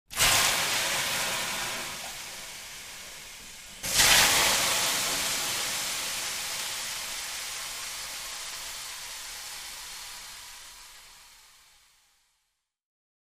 Звуки пара
Шипение пара в сауне от воды на раскаленные камни